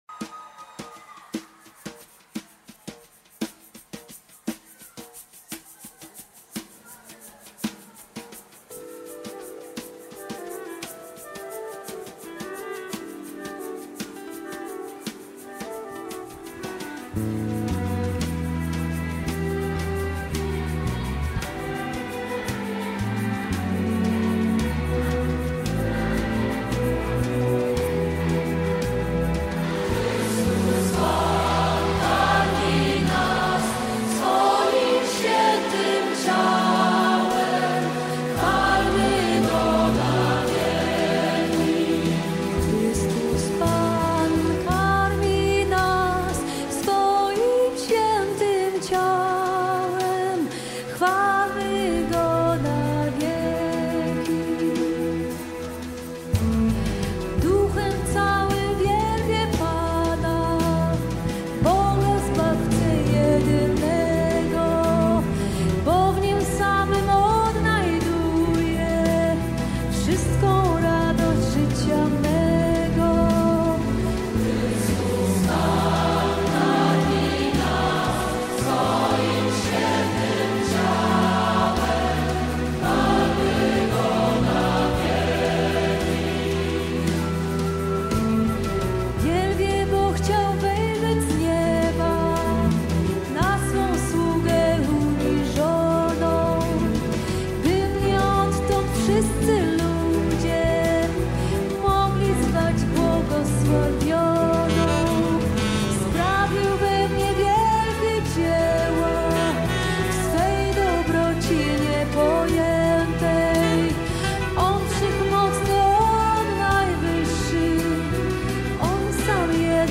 Rzeszów (Live)